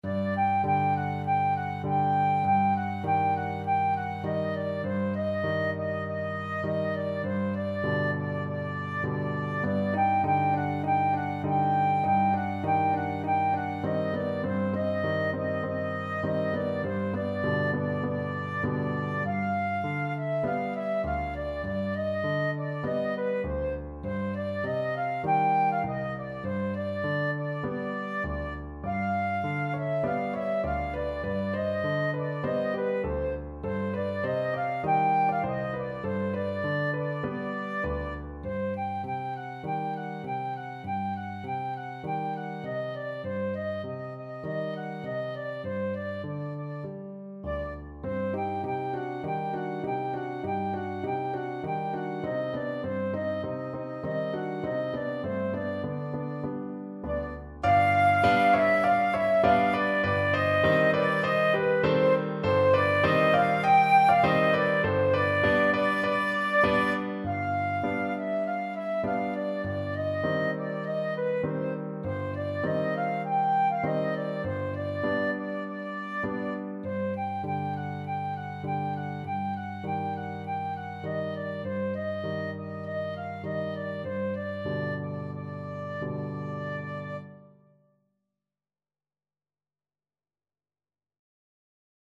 Flute
Traditional Music of unknown author.
G major (Sounding Pitch) (View more G major Music for Flute )
4/4 (View more 4/4 Music)
Moderato